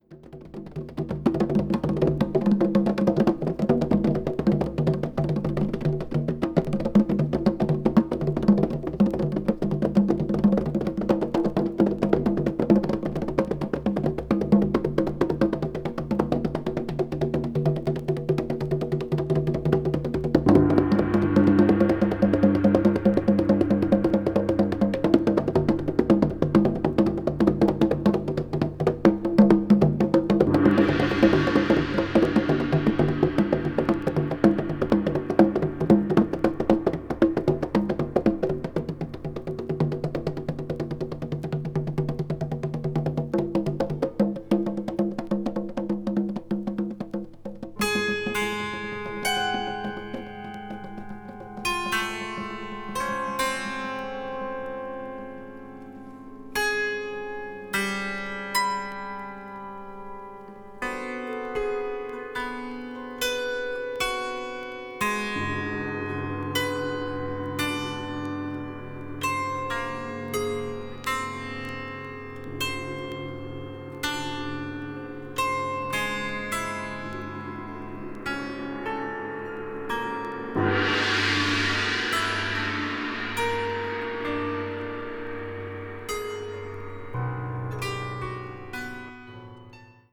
media : EX/EX(わずかにチリノイズが入る箇所あり)